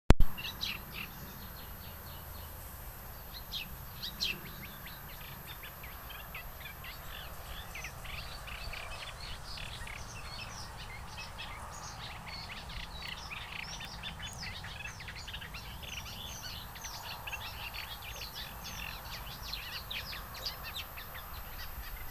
Piuttosto facile da sentire quando emette il suo caratteristico canto che è anche l'elemento più sicuro per riconoscerla dalla cannaiola verdognola.
cannaiola.wma